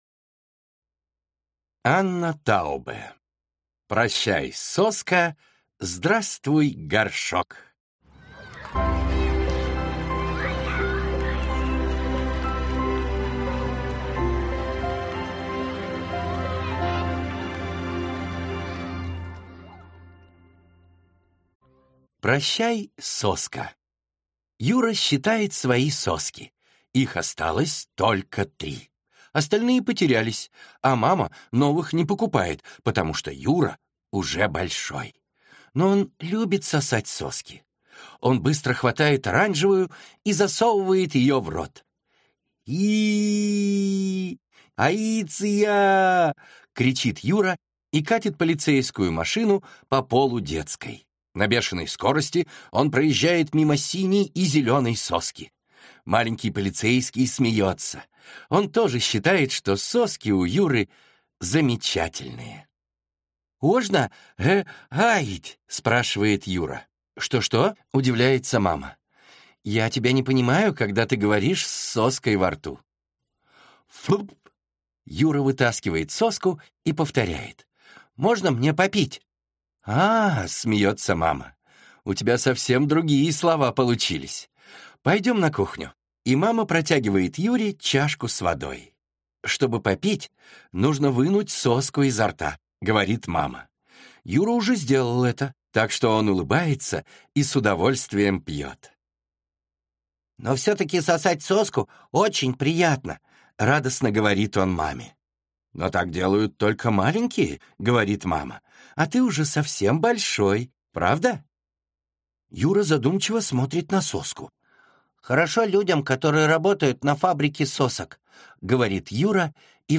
Аудиокнига Прощай, соска! Здравствуй, горшок!